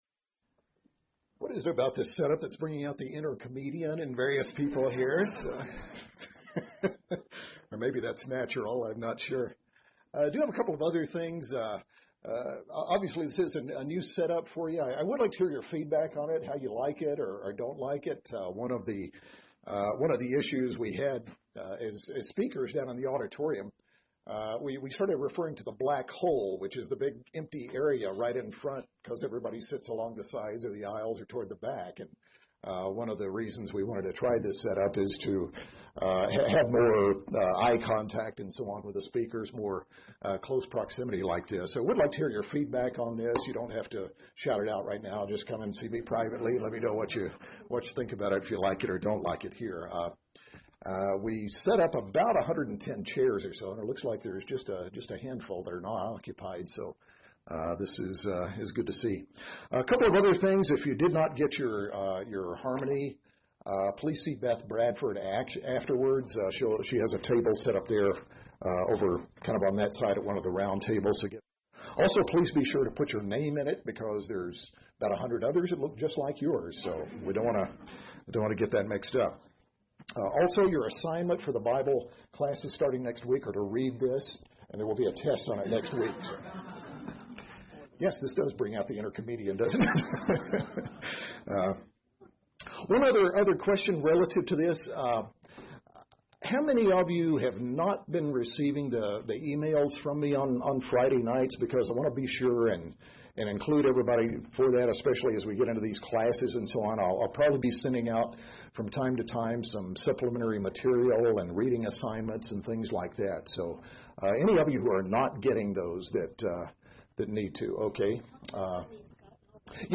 In this sermon we examine several key aspects of the political and religious background of the Gospels: How conditions in the Roman Empire established a climate in which the Messiah could come to start and build His Church and spread the gospel; who the Roman and Herodian rulers were who are mentioned in the Gospels; and the different religious and political groups that existed during the time of Christ's ministry the Pharisees, Sadducees, Essenes, Herodians, and Zealots.